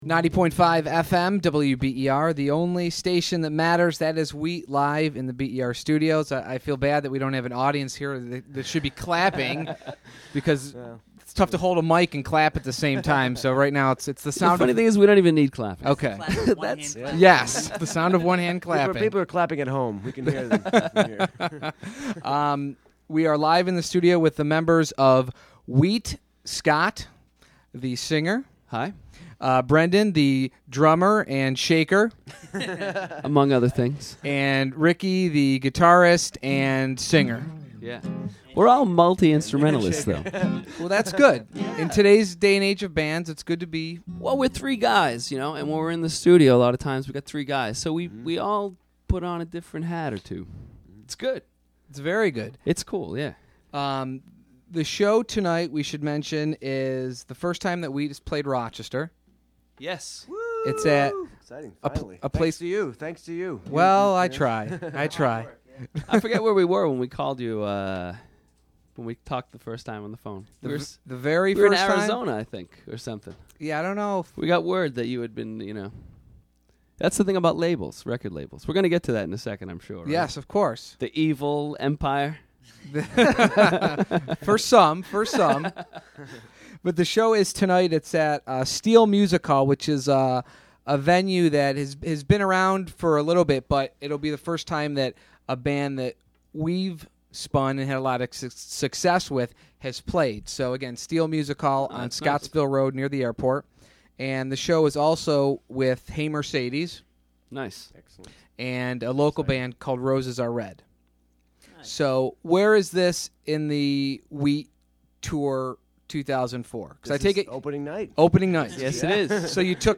02_Interview.mp3